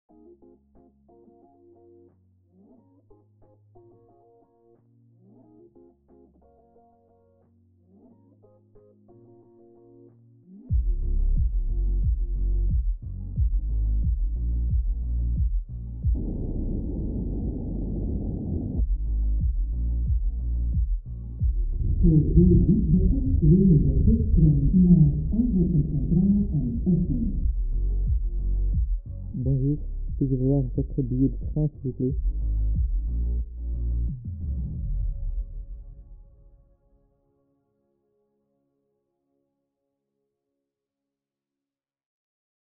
I've recorded all kinds of things, recorded,... Afterwards I edited this in Adobe Audition. 2 versions:
- Deaf version:
Users can click on the earpiece to hear what the deaf hear, therefore those 2 versions.